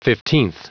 Prononciation du mot fifteenth en anglais (fichier audio)
Prononciation du mot : fifteenth